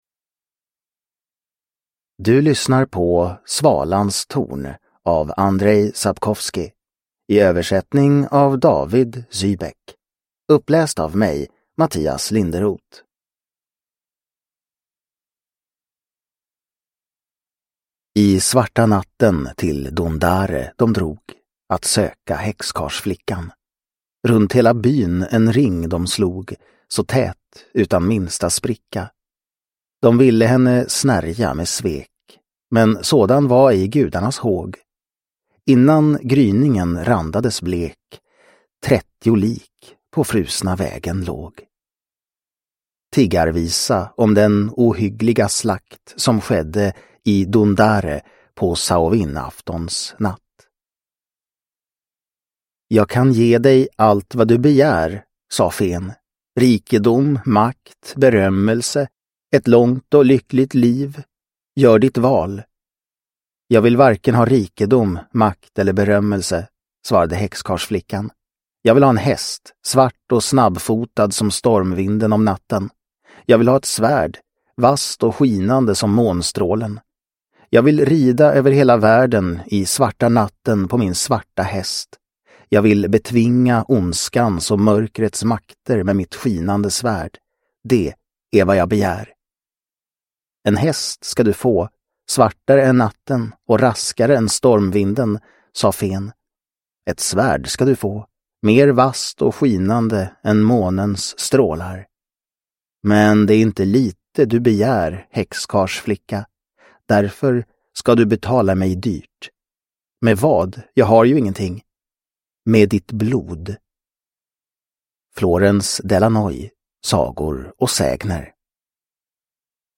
Svalans torn – Ljudbok – Laddas ner